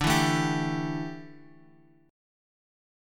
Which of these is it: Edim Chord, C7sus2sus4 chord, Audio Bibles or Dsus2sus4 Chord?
Dsus2sus4 Chord